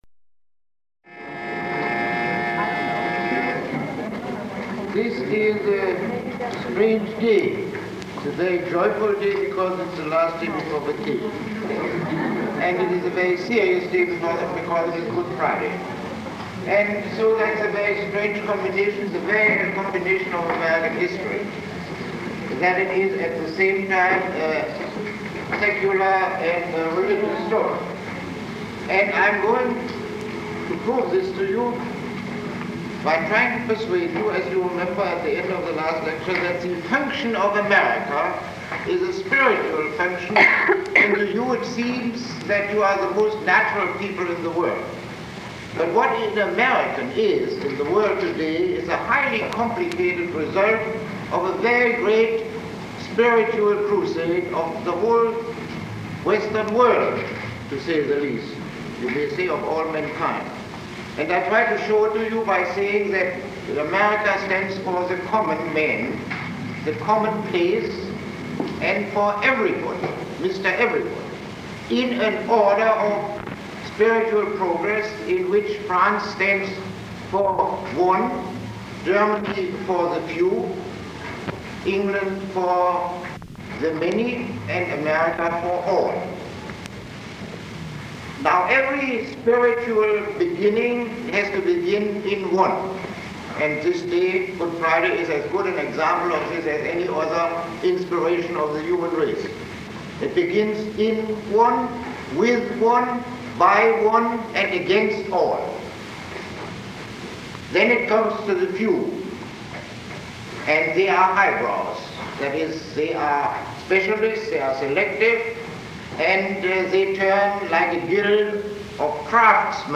Lecture 20